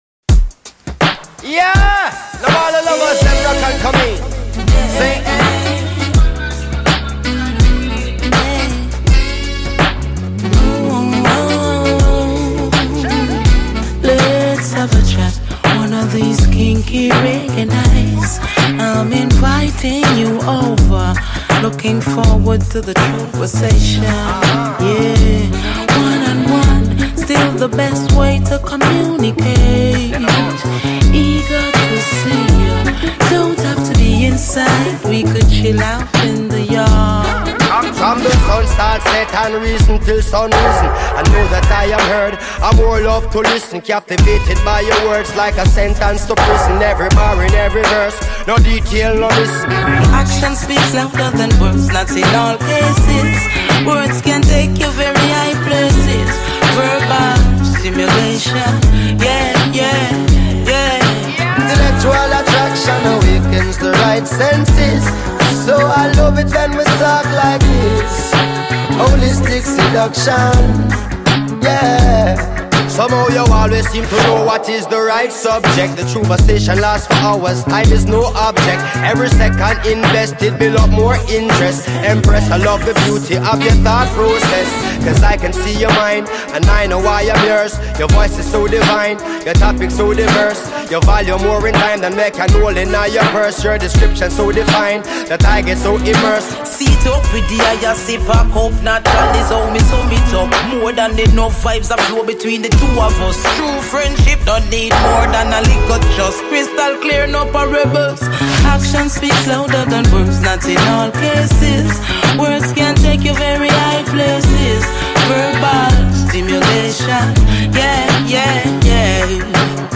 🎧 In sottofondo, una selezione musicale che riflette lo spirito del Bababoom: roots, dub, dancehall e vibrazioni positive in riva al mare.